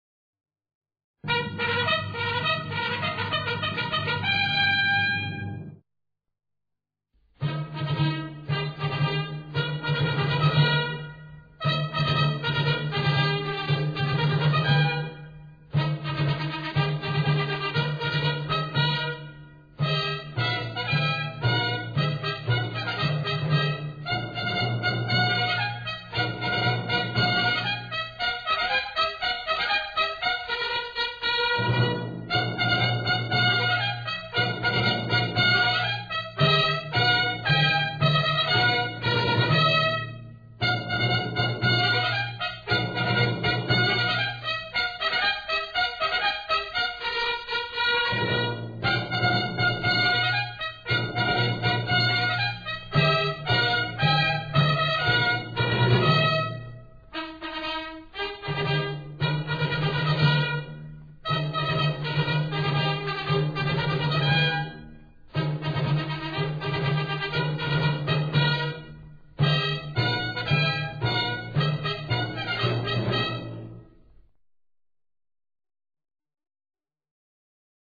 MARCHAS NAPOLEONICAS